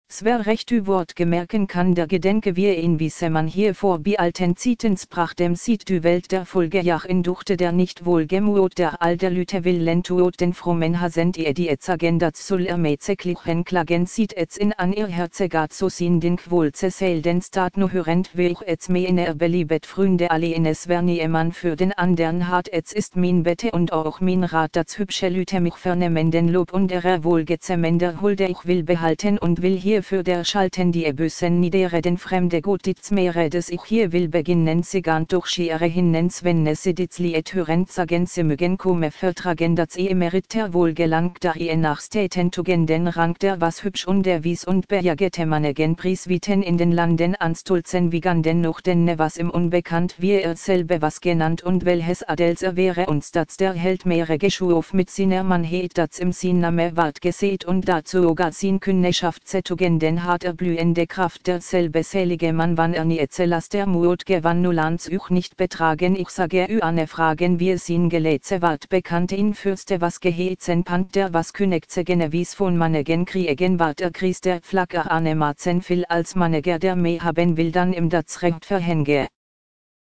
Wir drosseln deshalb ihr ungestümes Tempo etwas, indem wir nach jeder von ihr gelesenen Verszeile eine kleine Pause einfügen.
Etwas mehr als eine halbe Sekunde pro Zeile braucht Hedda auf meinem Computer nun zusätzlich, um ans Ende ihres Vortrags zu gelangen, und für meine Ohren sind das (insgesamt) sehr wohltuende 27 Sekunden: